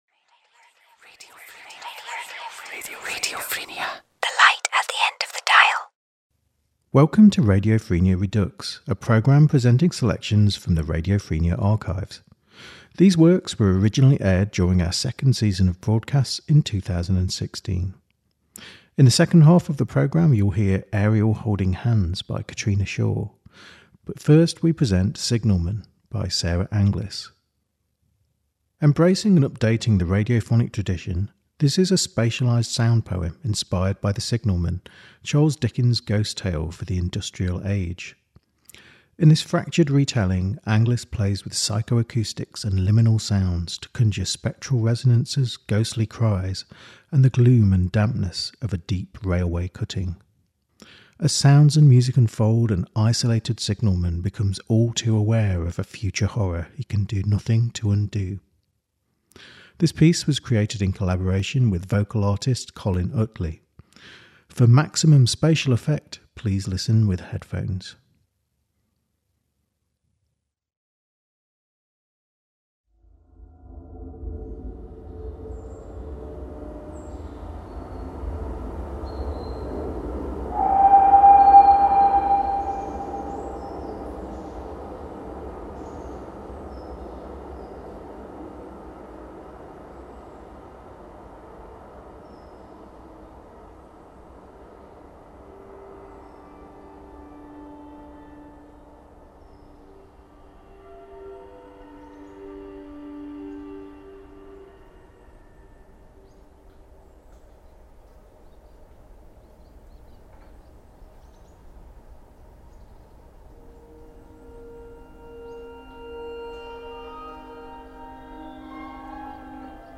For maximum spatial effect, please listen with headphones.